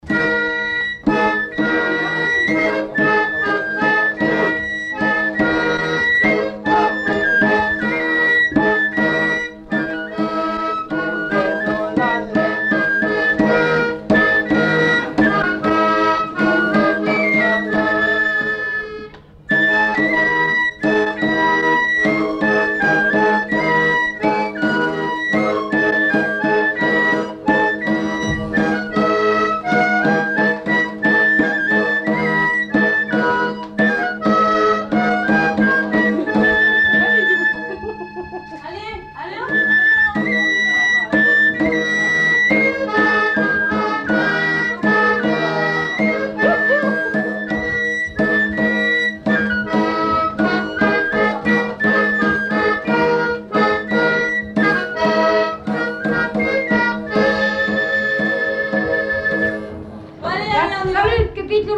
Aire culturelle : Béarn
Lieu : Castet
Genre : morceau instrumental
Instrument de musique : flûte à trois trous ; tambourin à cordes ; accordéon diatonique